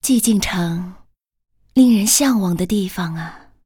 文件 文件历史 文件用途 全域文件用途 Dana_amb_01.ogg （Ogg Vorbis声音文件，长度3.7秒，95 kbps，文件大小：43 KB） 源地址:游戏语音 文件历史 点击某个日期/时间查看对应时刻的文件。